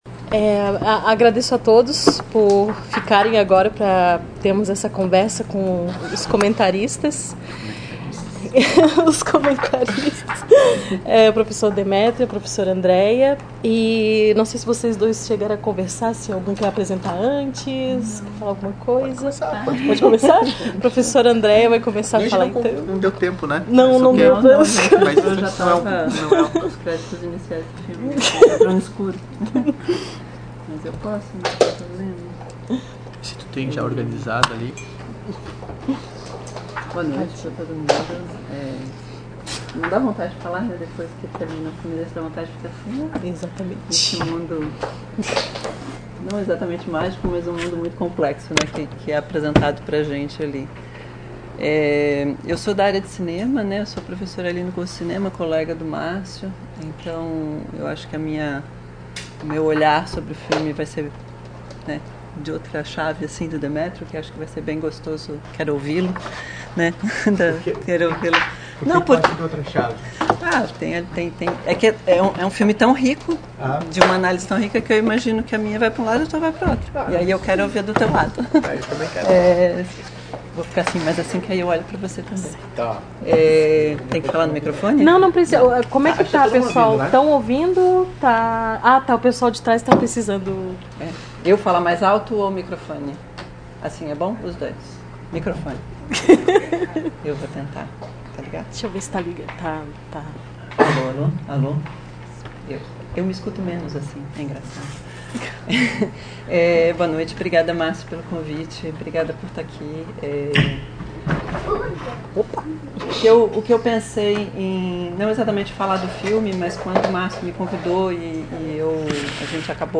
Comentários dos debatedore(a)s convidado(a)s
na sessão de exibição e debate do filme "A Canção do Oceano" (Song of the Sea, ano de produção: 2014), do diretor Tomm Moore, realizada em 13 de junho de 2019 no Auditório Elke Hering da Biblioteca Central da UFSC.